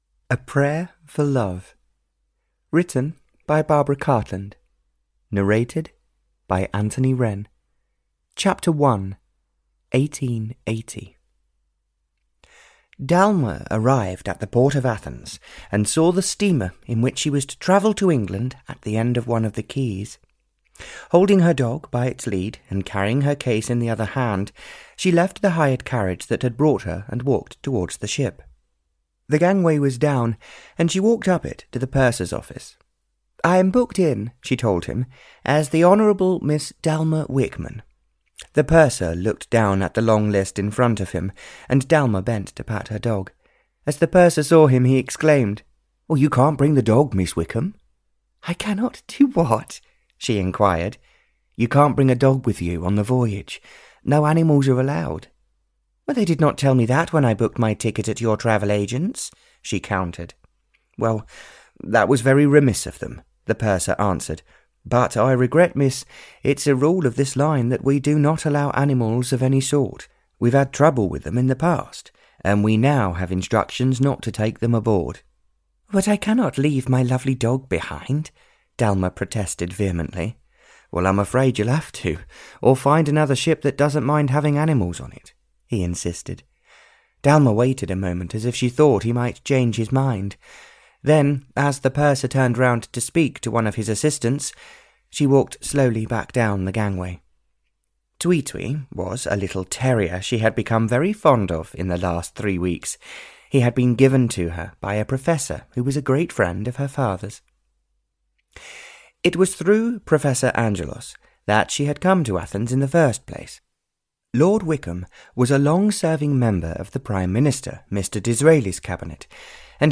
Audio knihaA Prayer for Love (Barbara Cartland's Pink Collection 98) (EN)
Ukázka z knihy